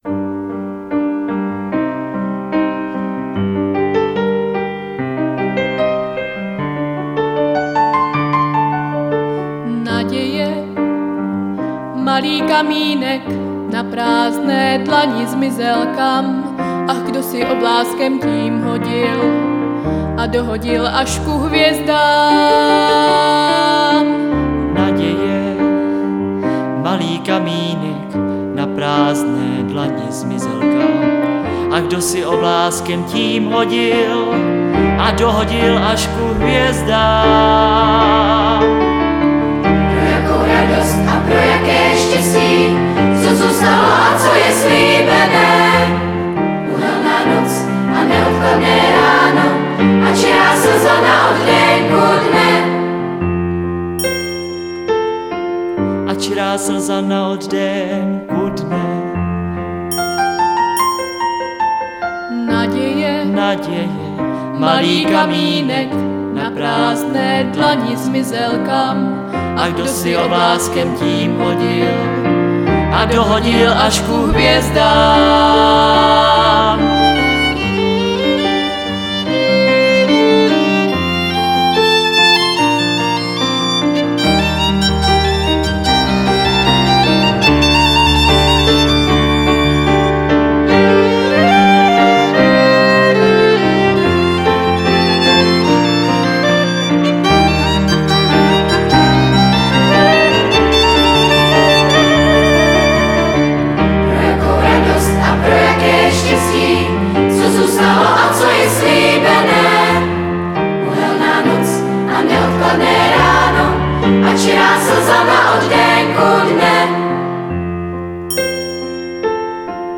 Pěvecké sbory